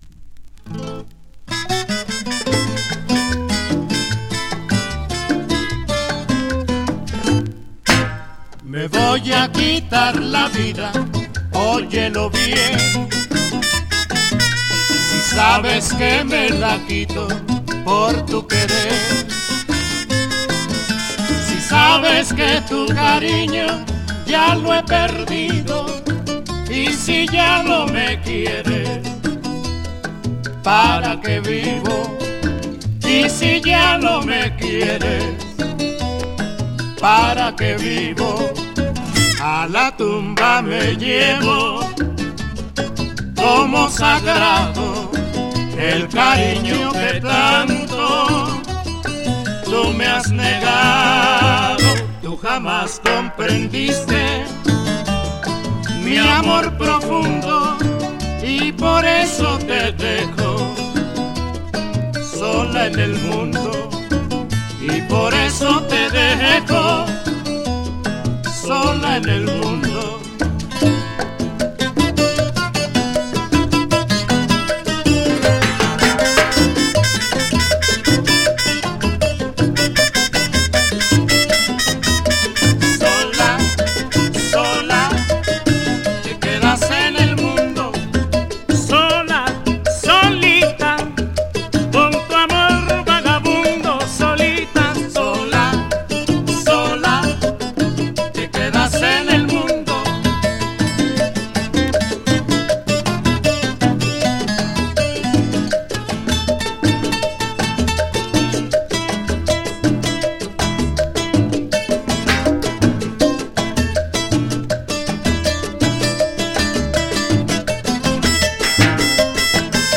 bolero-son